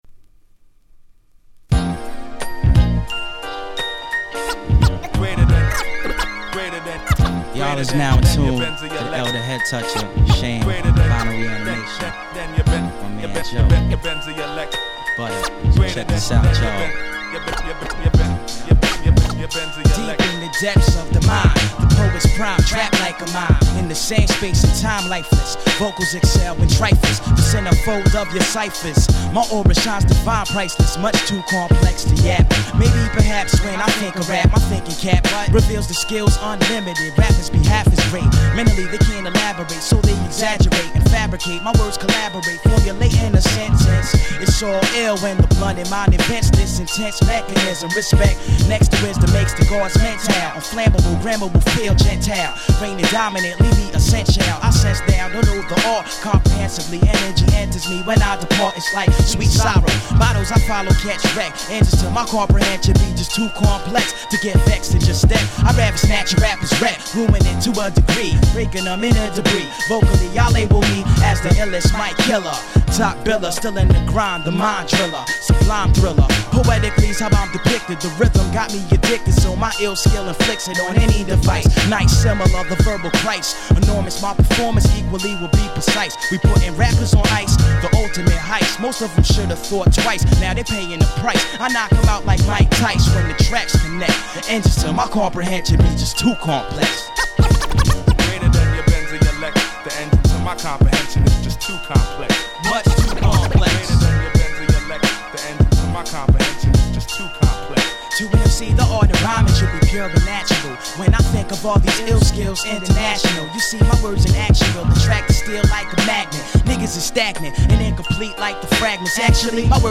97' Nice Underground Hip Hop !!
Under Ground Hip Hop Classics !!